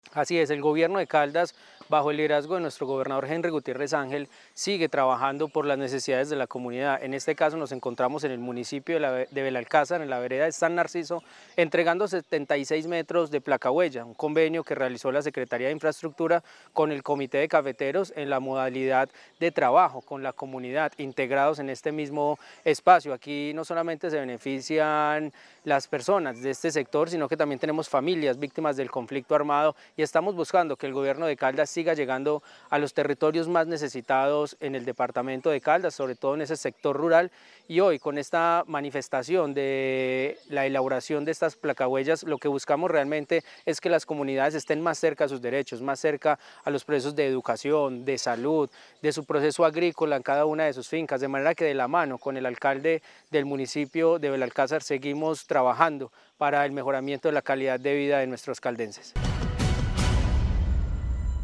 Ronald Fabián Bonilla Ricardo, gobernador encargado.